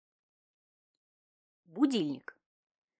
Аудиокнига О котах и не только. Стихи для детей | Библиотека аудиокниг